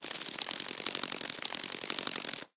beetle_wings_long_copy.ogg